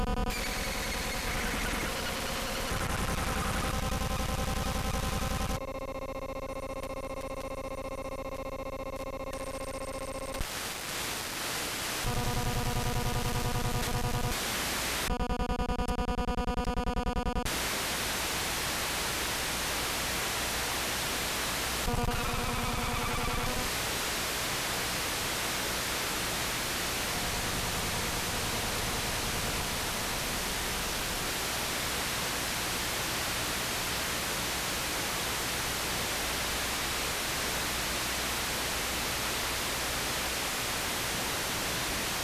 Uknown Buzzing Signal , Very Strong around a 25km area. Main frequency 137.675Mhz